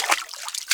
WATER 2.WAV